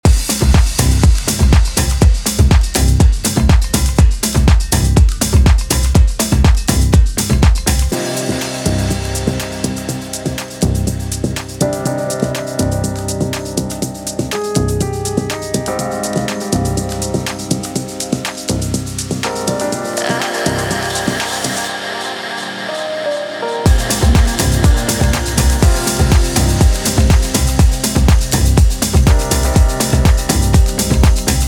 Mixing & Mastering